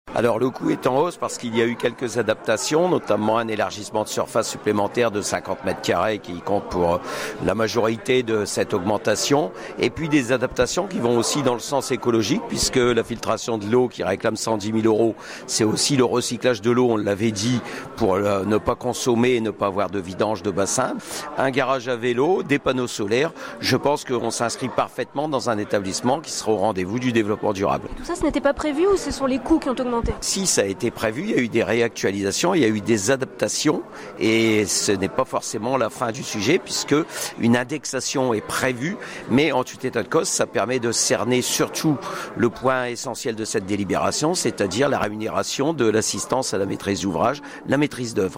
Les explications de Guy Allemand, vice-président de la communauté d’agglomération en charge des sports et des établissements communautaires à caractère sportif.